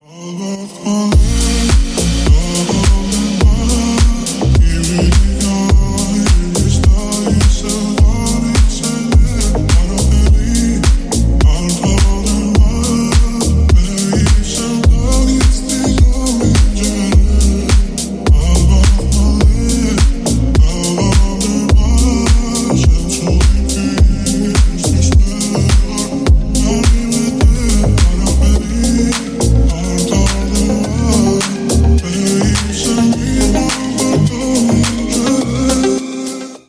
deep house
клубные